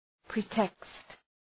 {‘pri:tekst}